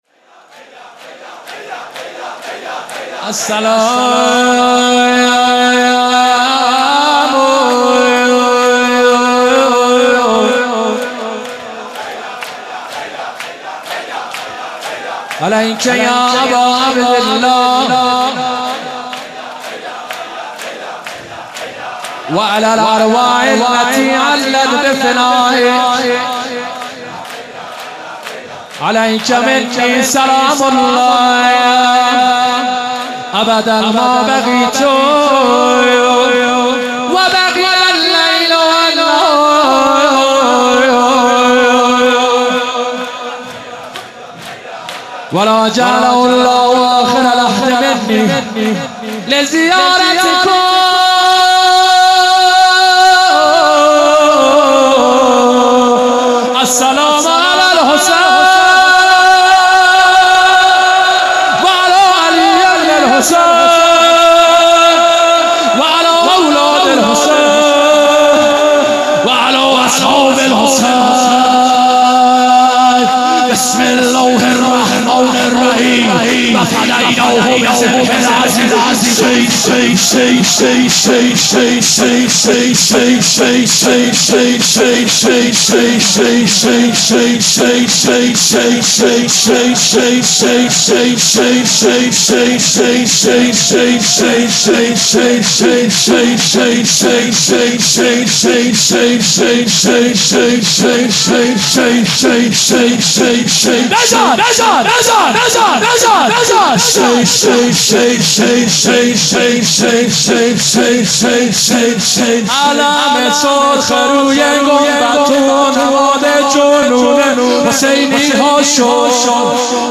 مناسبت : شب بیست و یکم رمضان - شب قدر دوم
قالب : شور